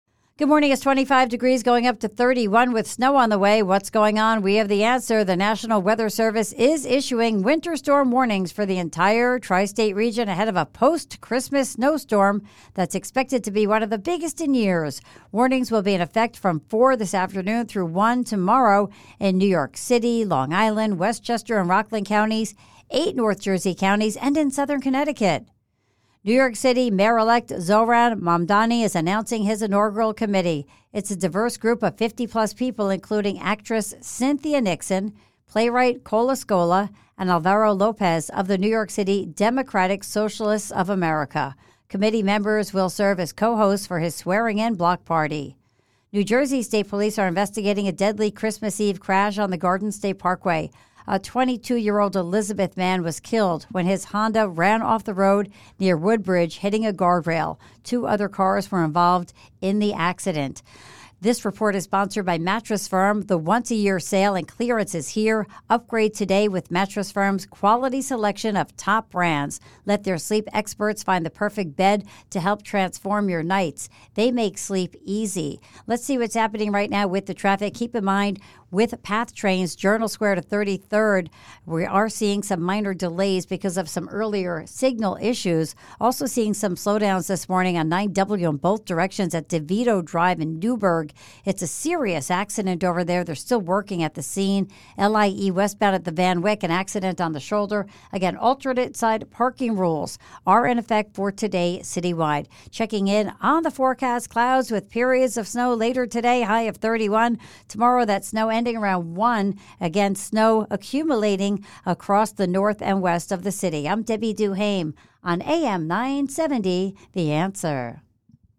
Here are some sound bites from AM970 The Answer
AM 970 The Joe Piscopo show News, Traffic Report